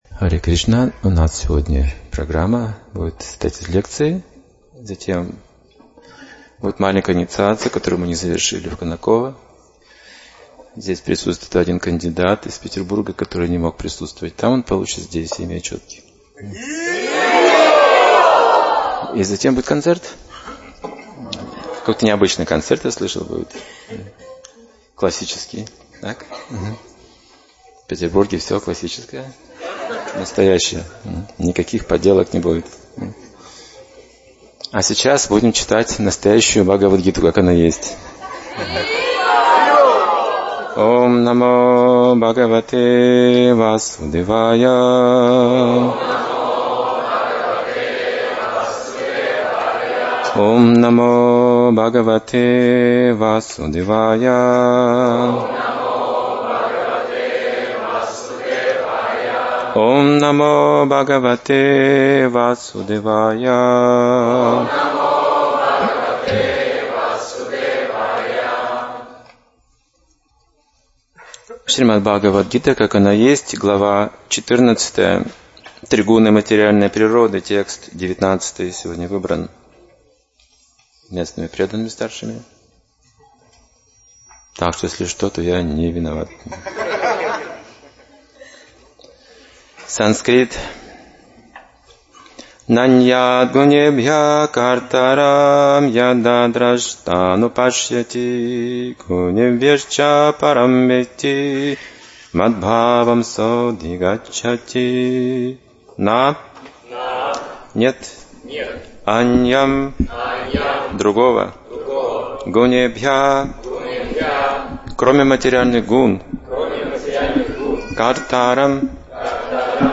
Лекции
Санкт-Петербург